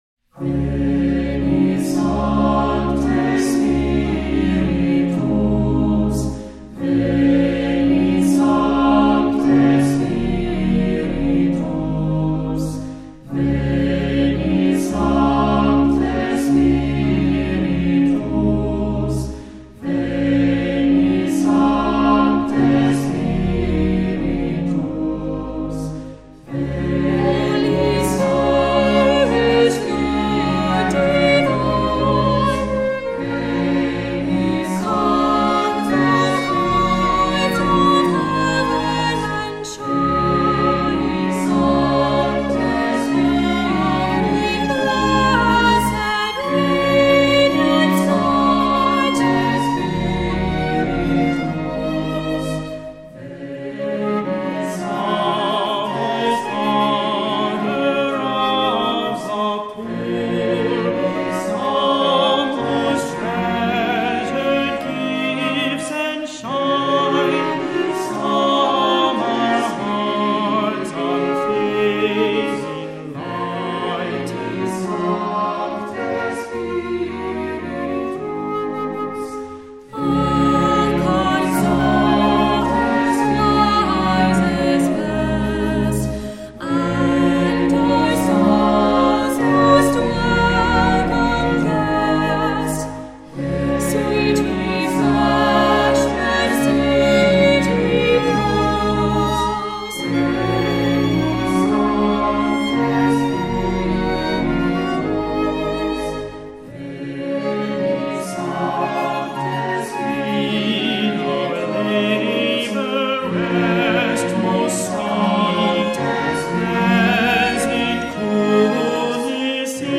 Voicing: Cantor,Assembly,SATB